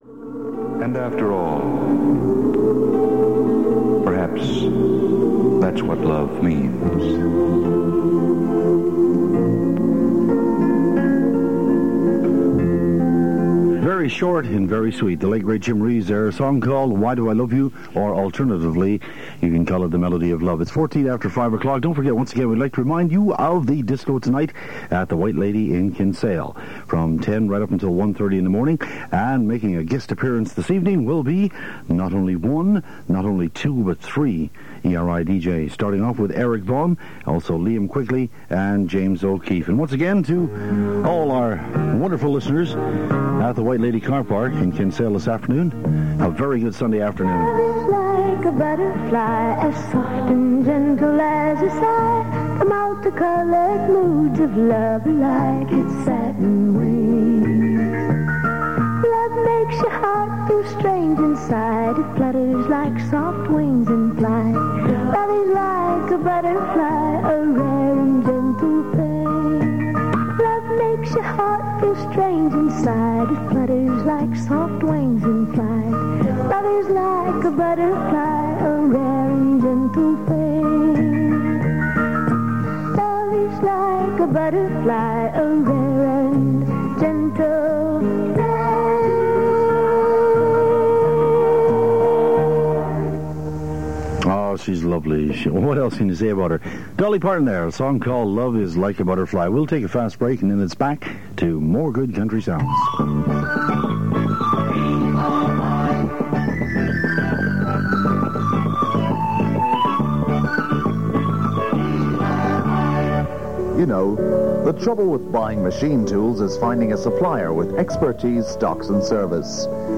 It was made during an outside broadcast from Kinsale before an event later that evening featuring ERI DJs. Reflecting the station’s growing popularity after its expansion earlier that year, several adverts are heard from businesses in the city and all around the county.